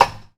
Snare (25).wav